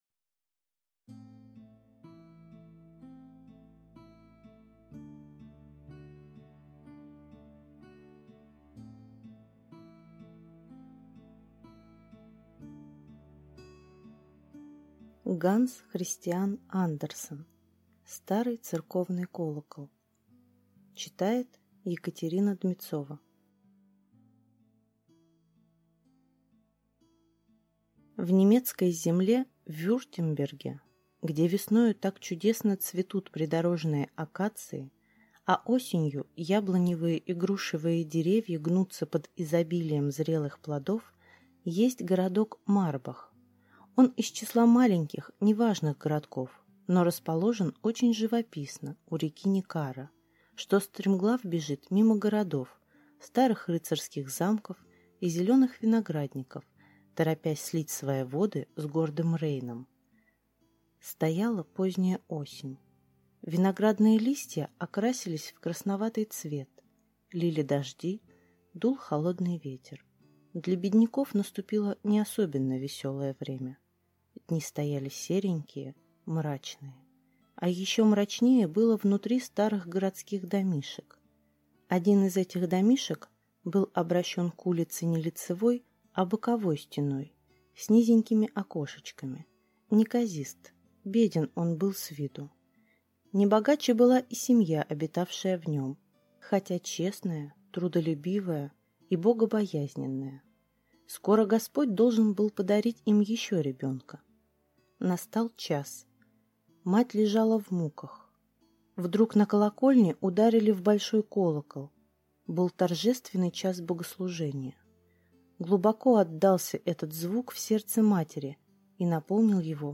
Аудиокнига Старый церковный колокол | Библиотека аудиокниг